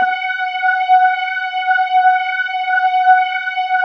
cch_synth_loop_chorus_125_F#m.wav